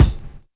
WU_BD_303.wav